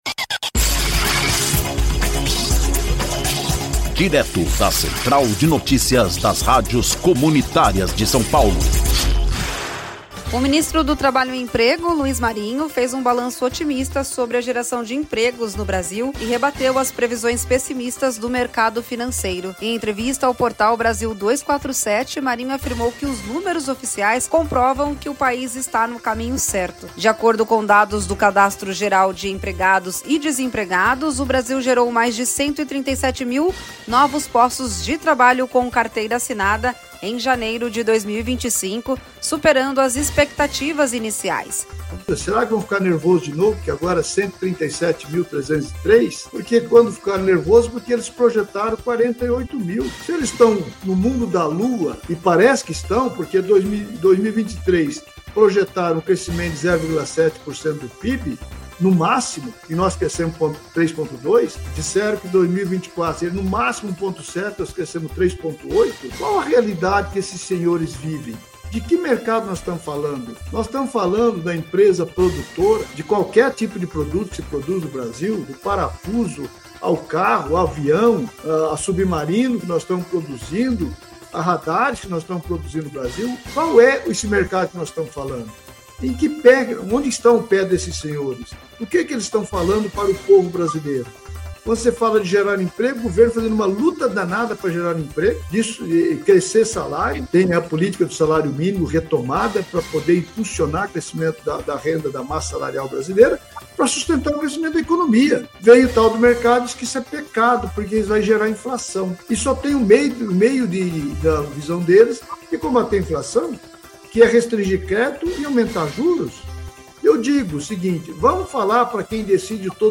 Ouça a notícia: Ministro Luiz Marinho destaca geração de mais de 137 mil empregos formais em janeiro de 2025